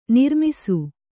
♪ nirmisu